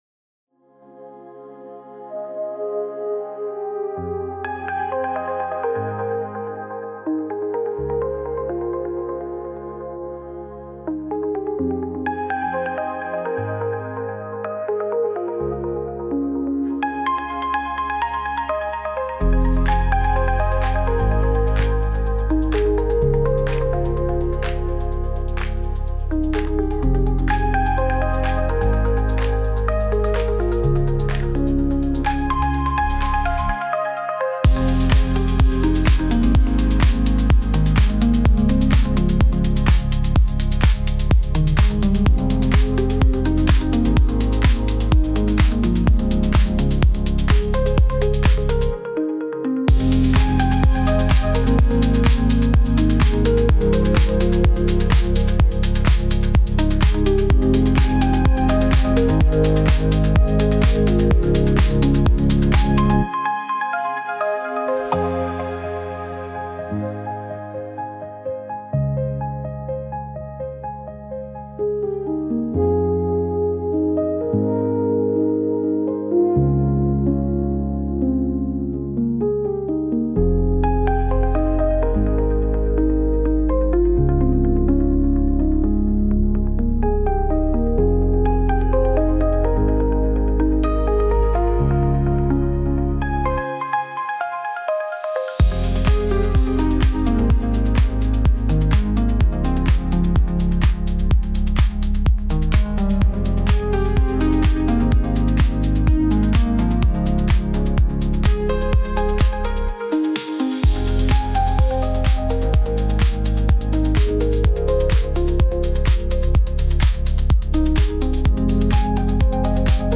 Pop, Electronic, Corporate
Groovy, Laid Back
126 BPM